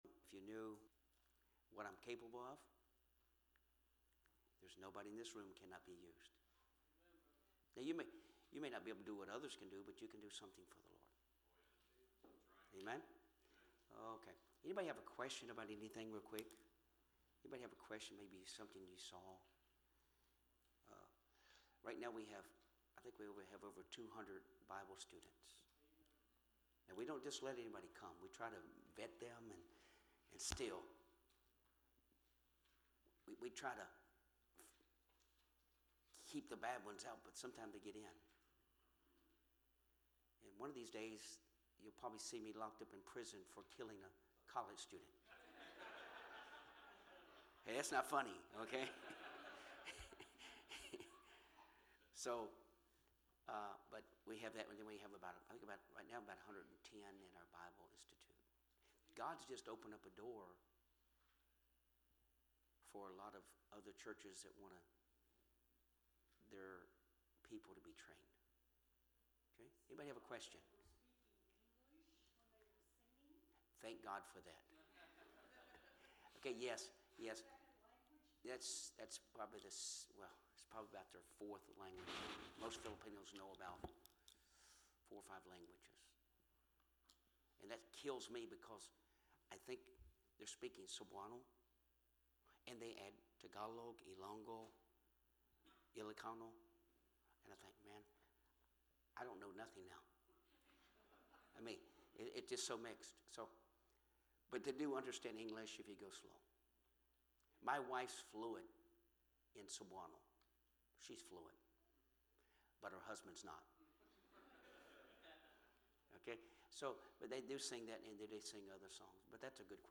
Preaching from the Pulpit | First Baptist Church